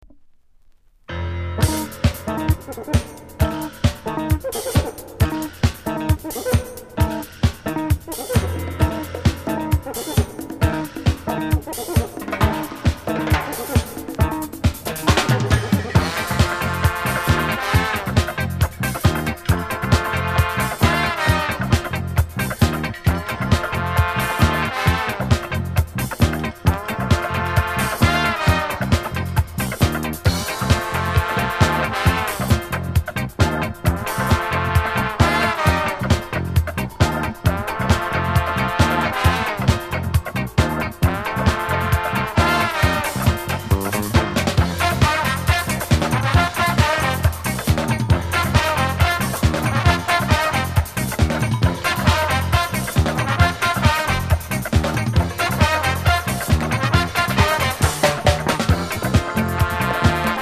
コメント 裏面にLATINテイストのFUNKY INST収録!!※オリジナルのジャケット付き（VG）シワ